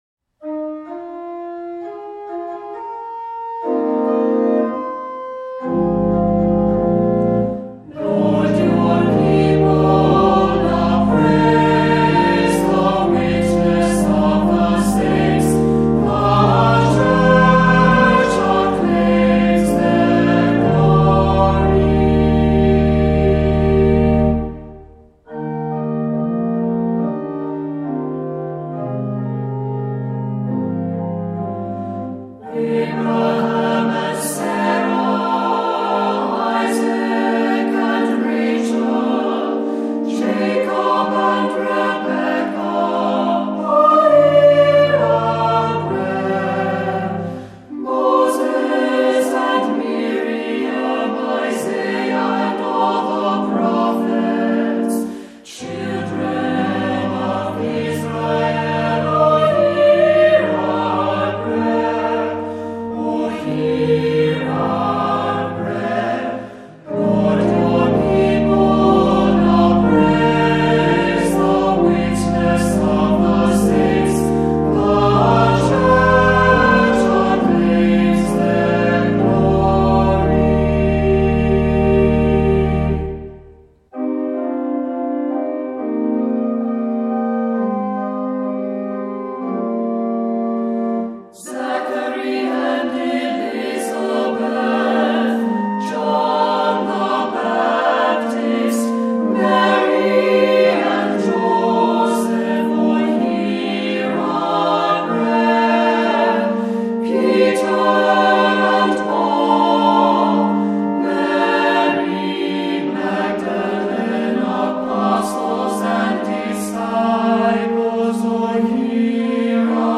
Voicing: optional SATB; Descant; Cantor; Assembly